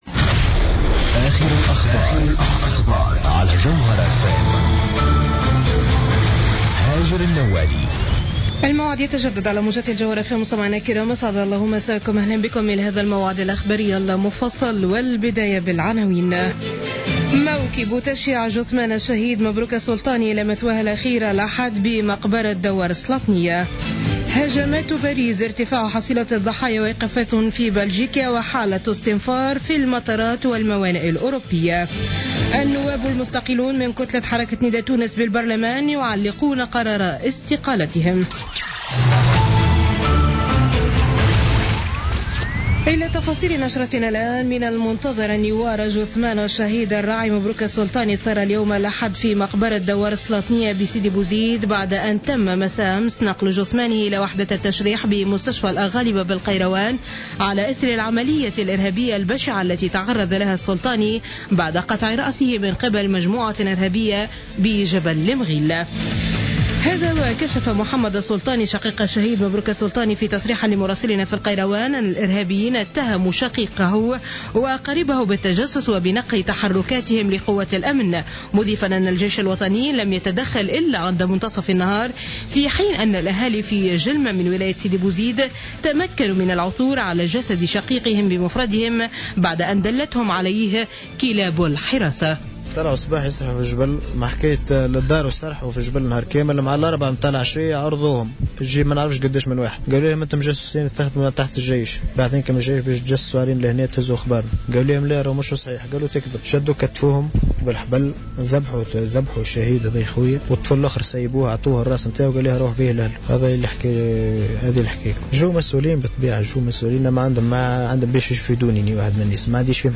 نشرة أخبار منتصف اليل ليوم الأحد 15 نوفمبر 2015